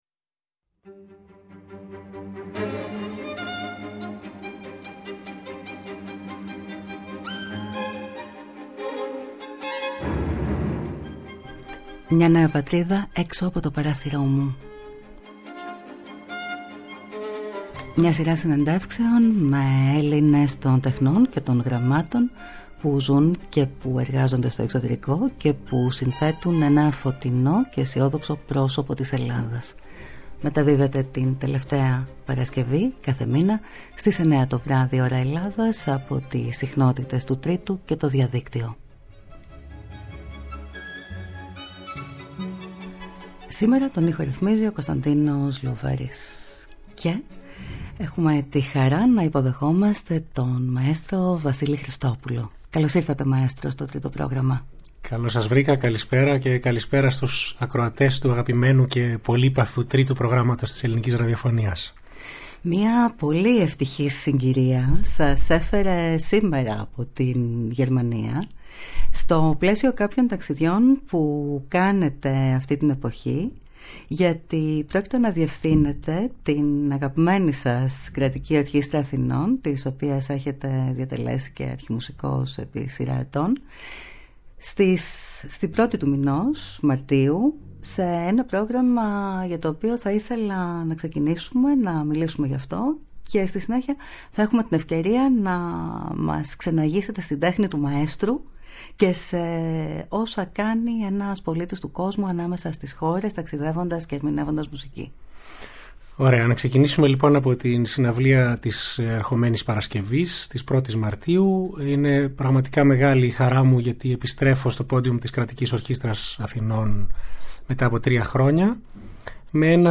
Μια σειρά συνεντεύξεων με Έλληνες των τεχνών και των γραμμάτων που ζουν και εργάζονται στο εξωτερικό και που συνθέτουν ένα φωτεινό και αισιόδοξο πρόσωπο της Ελλάδας.